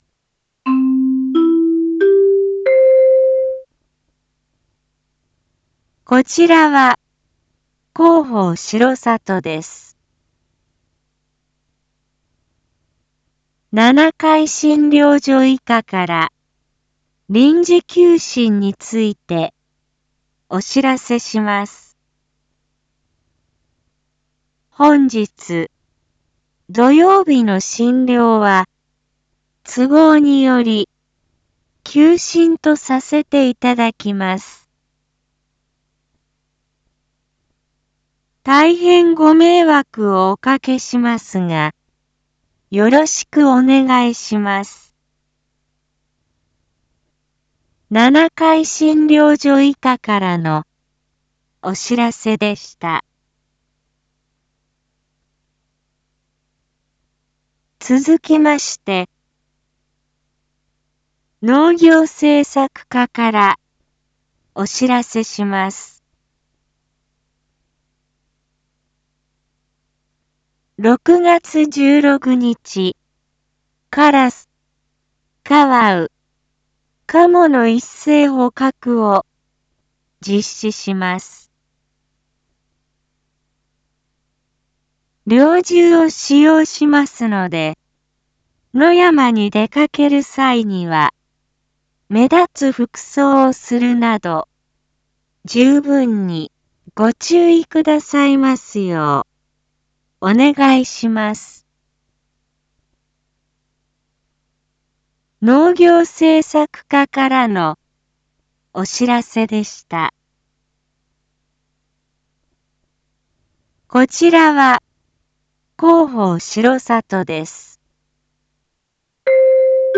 Back Home 一般放送情報 音声放送 再生 一般放送情報 登録日時：2024-06-15 07:02:04 タイトル：②七会診療所医科臨時休診のお知らせ インフォメーション：こちらは広報しろさとです。